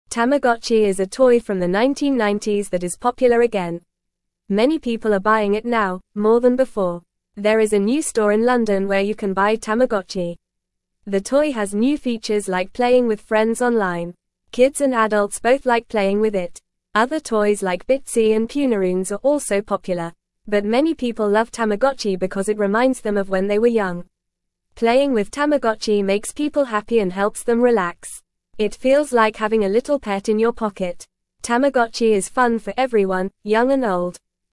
Fast
English-Newsroom-Beginner-FAST-Reading-Tamagotchi-Toy-Makes-People-Happy-and-Relaxed.mp3